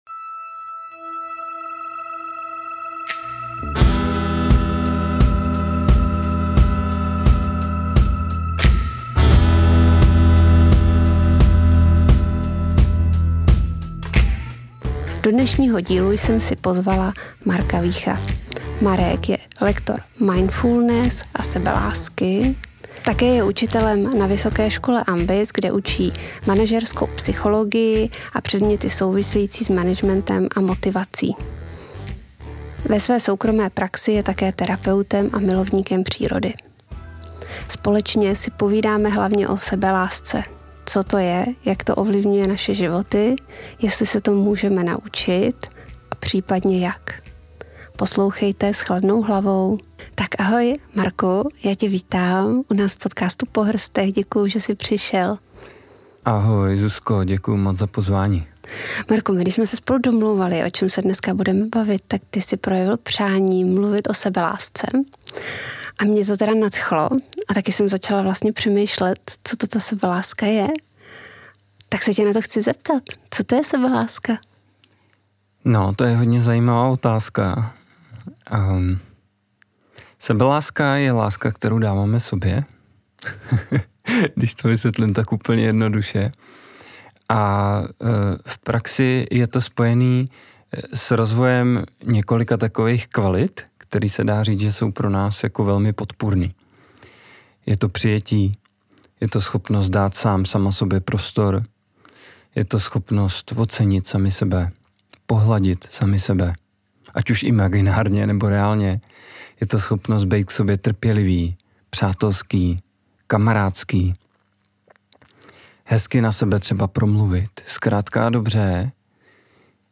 V rozhovoru si povídáme především o sebelásce a tématech s ní spojených. Dozvíte se, jak sebeláska ovlivňuje naše životy a zda je možné se jí naučit.